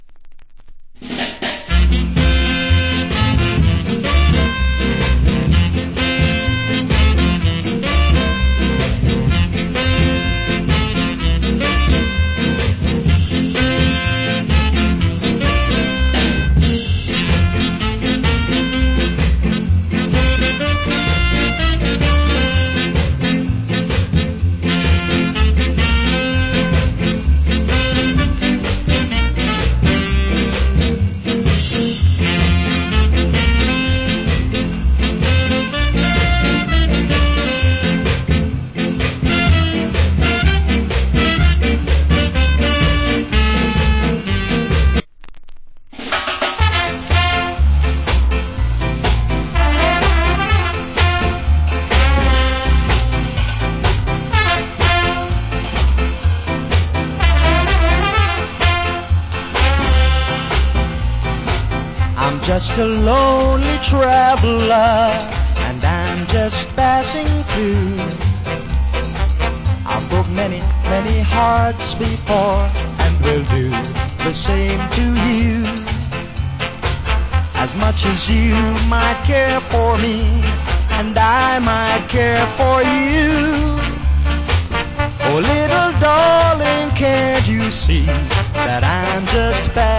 Wicked Ska Inst.